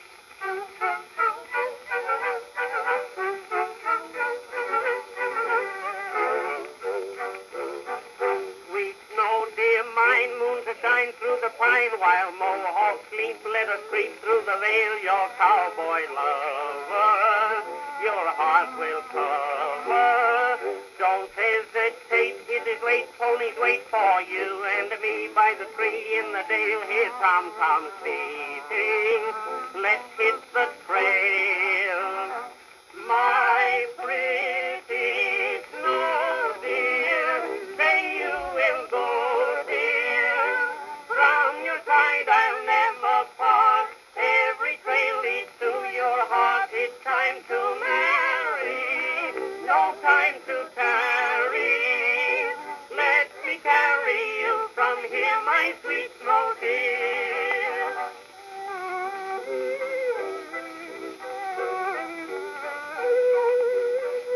This late version of the Edison Standard Phonograph was introduced in November, 1911.
It has also been fitted with a beautiful red iridescent morning glory horn with hand-painted flowers.
E-Standard-With-Morning-Glory.mp3